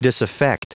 Prononciation du mot disaffect en anglais (fichier audio)
Prononciation du mot : disaffect